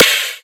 • Rich Top End Trap Snare Sample C# Key 120.wav
Royality free snare single hit tuned to the C# note.
rich-top-end-trap-snare-sample-c-sharp-key-120-ZZF.wav